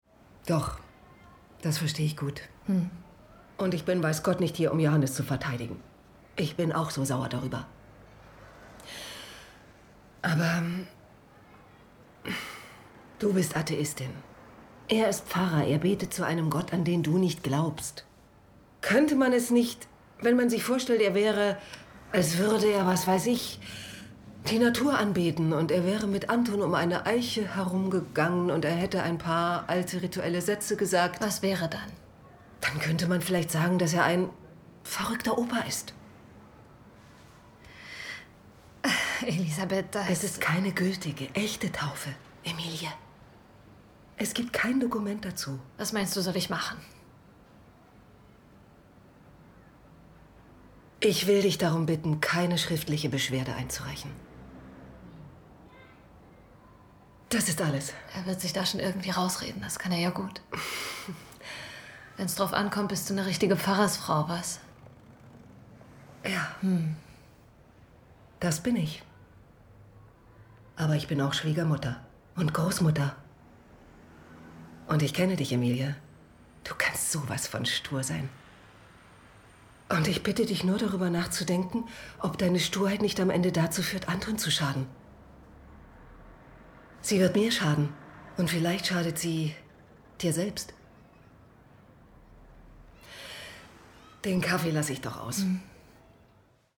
Synchron / Serienhauptrolle